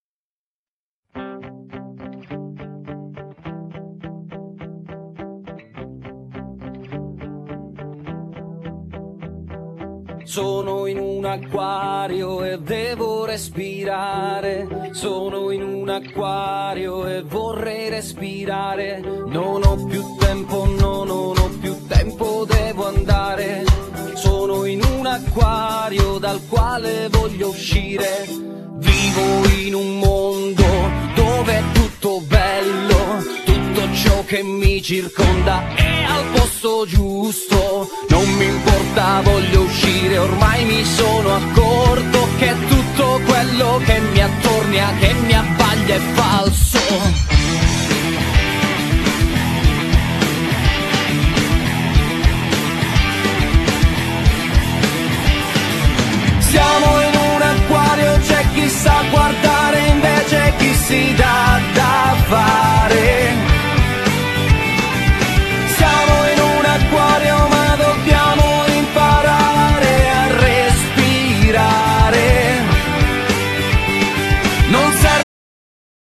Genere : Pop / Rock